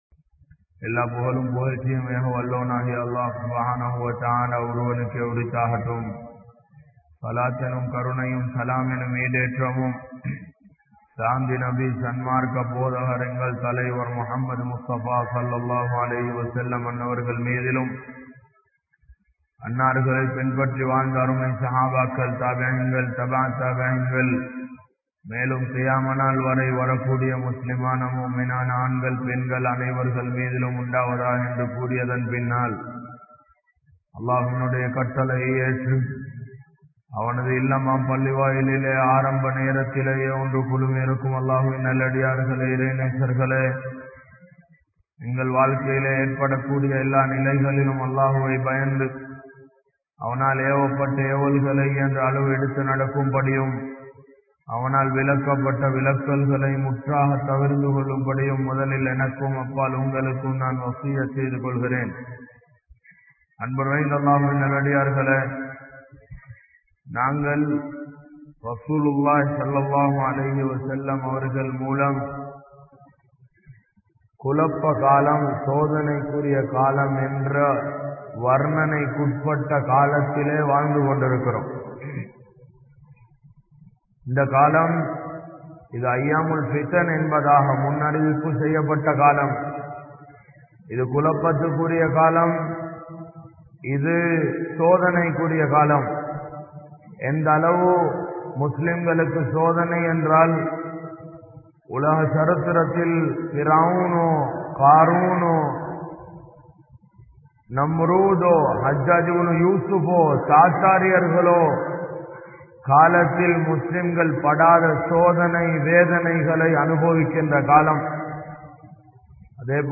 Naveena Jahiliyyavum Inraya Muslimkalum(நவீன ஜாஹிலியாவும் இன்றைய முஸ்லீம்களும்) | Audio Bayans | All Ceylon Muslim Youth Community | Addalaichenai
Grand Jumua Masjidh